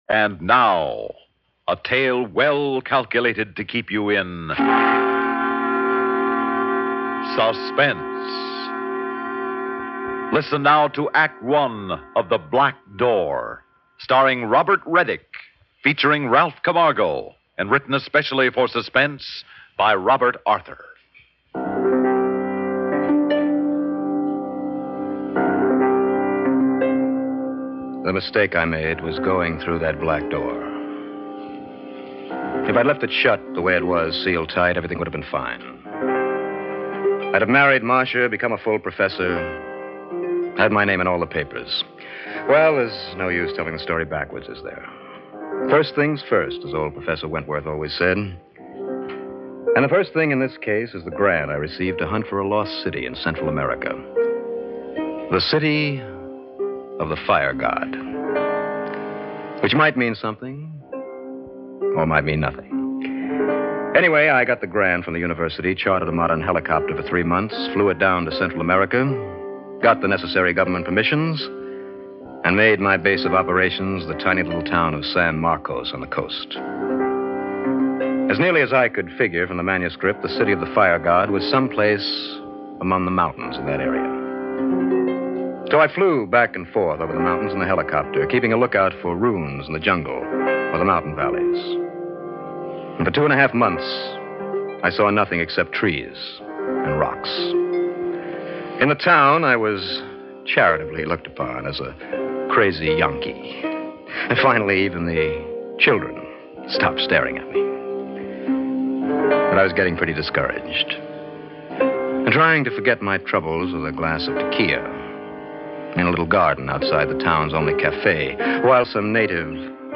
On this episode of the Old Time Radiocast we present you with two stories from the classic radio program Suspense!